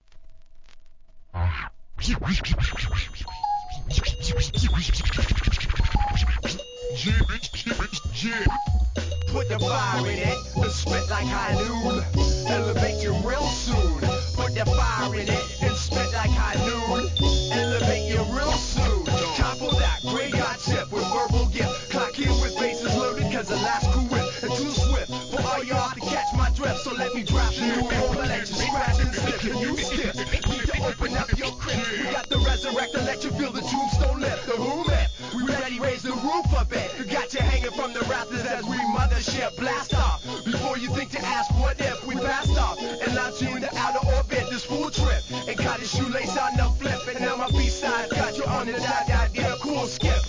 HIP HOP/R&B
CaliforniaはSan Diegoヒップホップ・グループ!